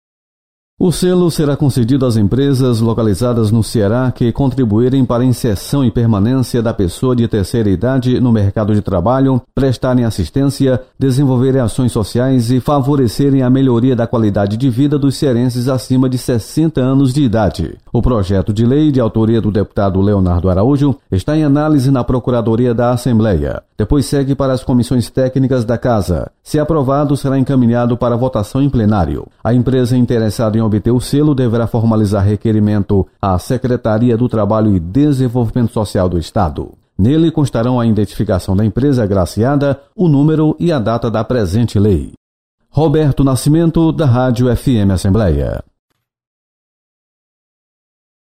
Projeto institui Selo Empresa Amiga do Idoso. Repórter